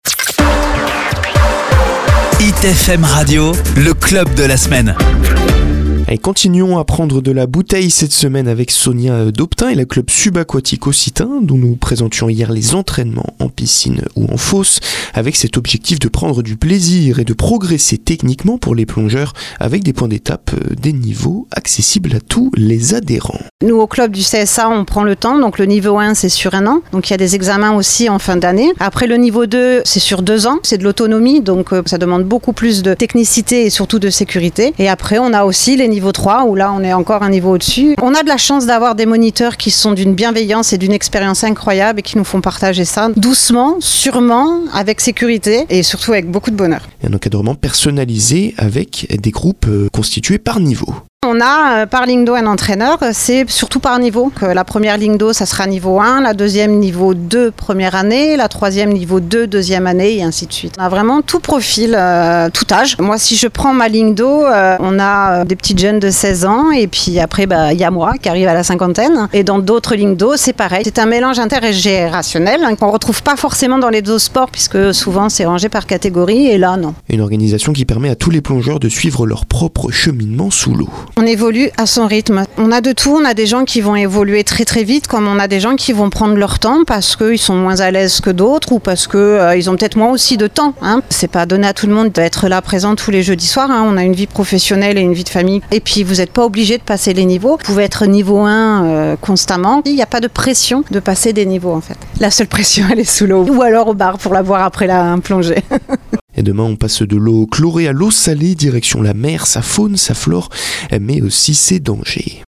LE CSA PASSE A LA RADIO SUR HIT FM